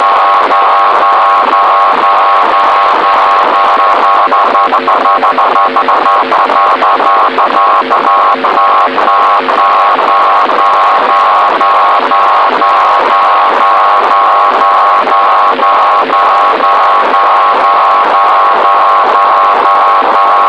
Wetterfaxe werden zu festgelegten Zeiten (zum Beispiel diesen hier) über den Sender Pinneberg und vom Sender Northwood (UK) ausgestrahlt und man kann sie mittels Kurzwellenempfänger PC-Soundkarte und einem geeigneten Programm entschlüsseln.
Nach einigem herumgekurbele an den Empfangsfrequenzen hatte ich ein halbwegs brauchbares Signal.
so an (habs mal mitgeschnitten, damit der geneigte Kosmonaut auch ungefähr weiss, wonach er suchen muss)
Wetterfax.mp3